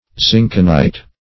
Zinkenite \Zink"en*ite\ (-en*[imac]t), n. [From Zinken, director